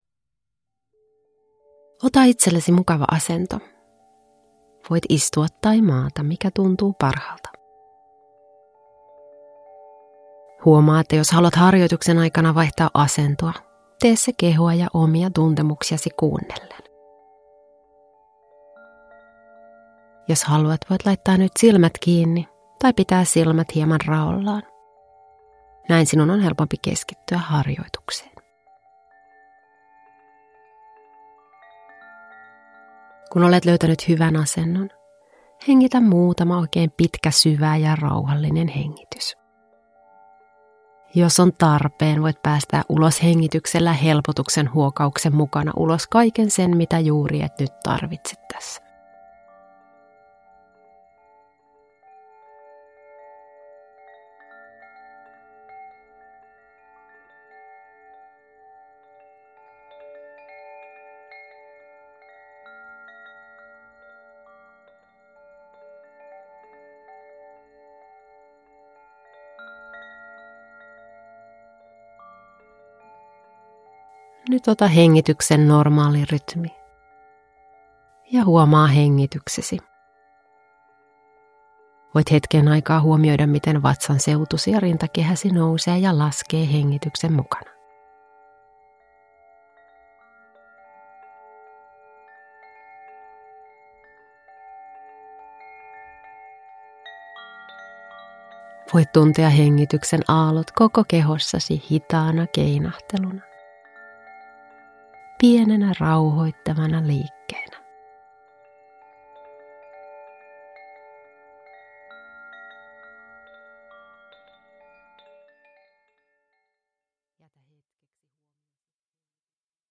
Lempeyttä itselle – Ljudbok – Laddas ner